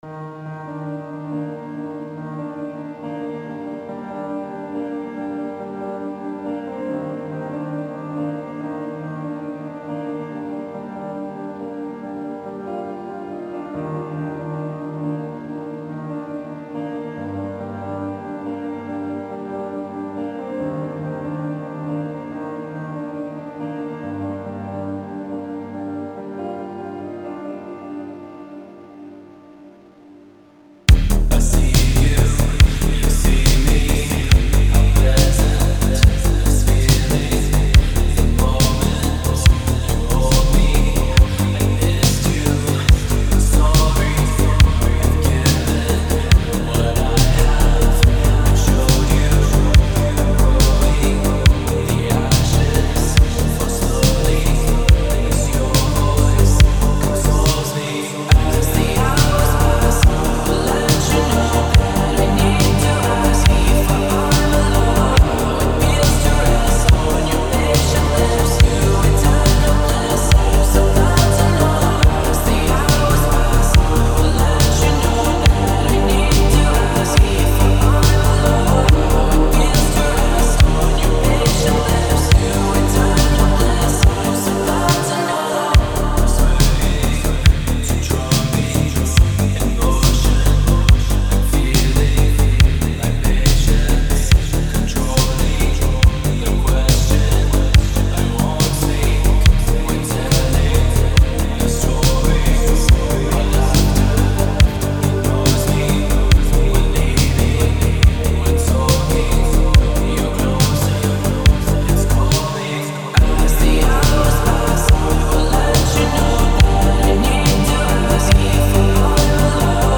Фонк музыка
Phonk музыка